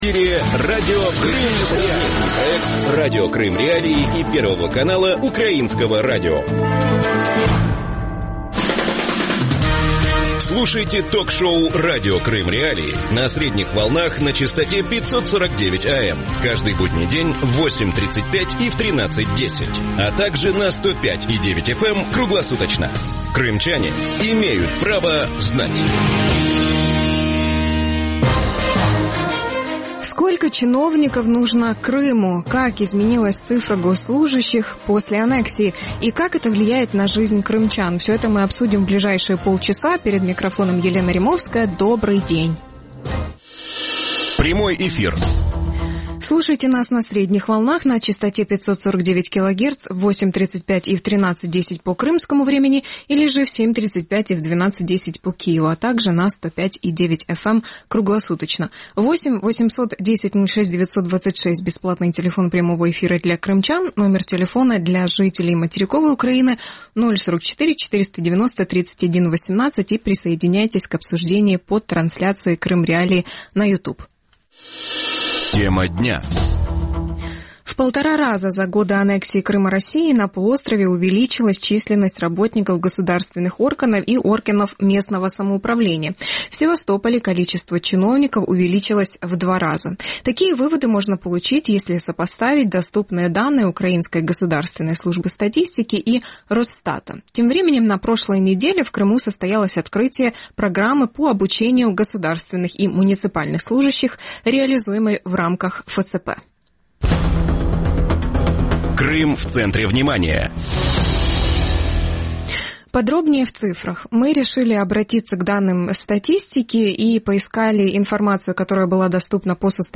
Сколько чиновников на душу населения в соседней России? И как обстоит дело с количеством чиновников на материковой Украине? Гости эфира